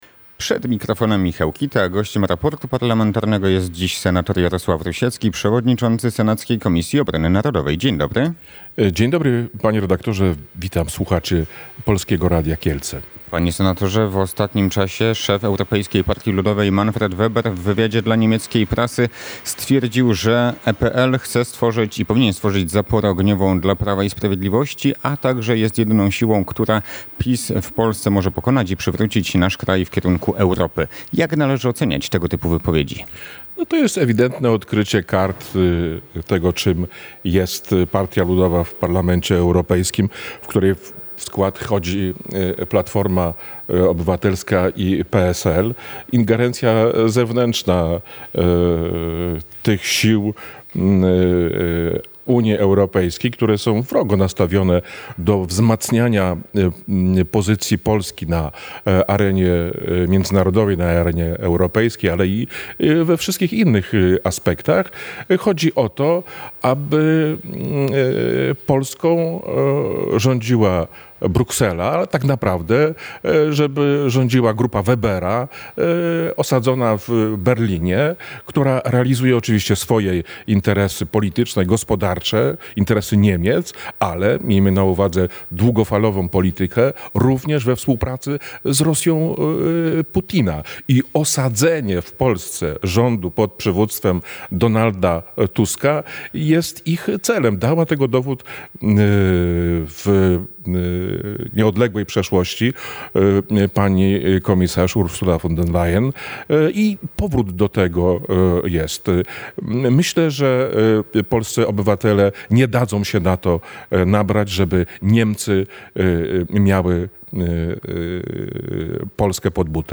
– Słowa przewodniczącego EPL mają na celu realizacje niemieckich interesów gospodarczych w Polsce – stwierdził senator Jarosław Rusiecki, przewodniczący senackiej Komisji Obrony Narodowej w Raporcie Parlamentarnym na antenie Radia Kielce.